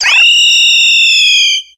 Cries
STARAPTOR.ogg